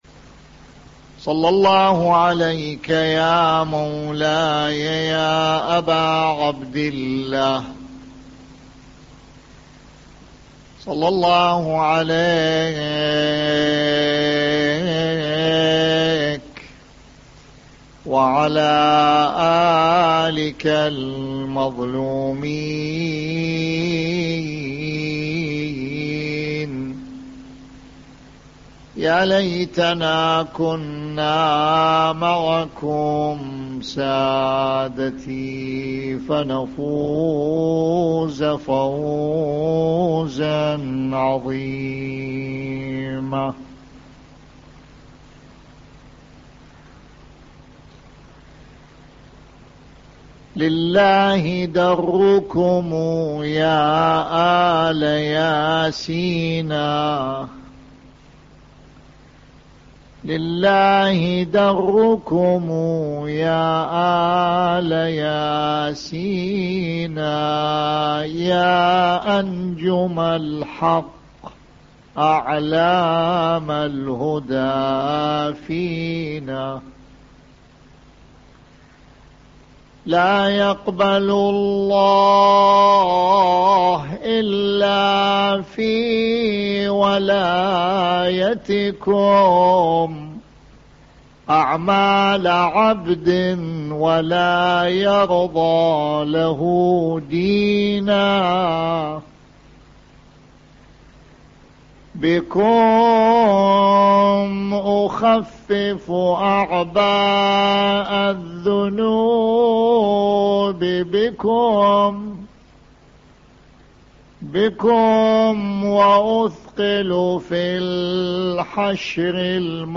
24/10/2005 محاضرات صوتية أترك تعليق 21,562 الزيارات